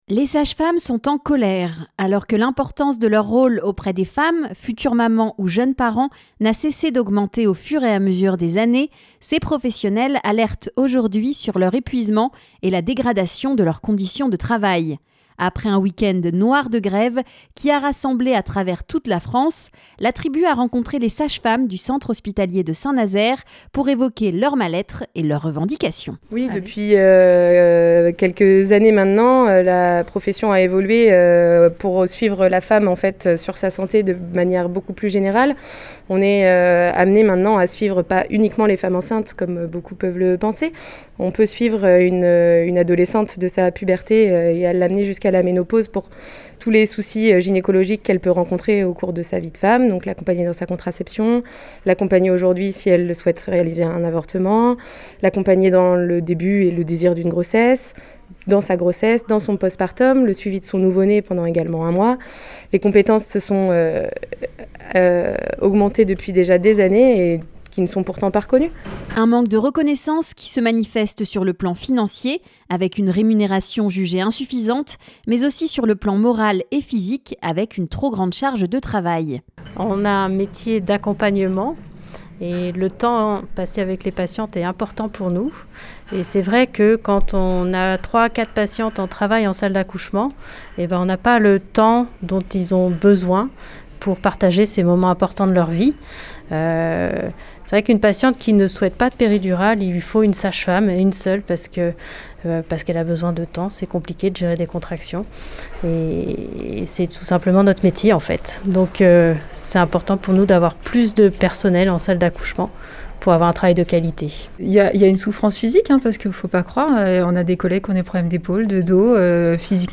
Reportage_SagesFemmes.mp3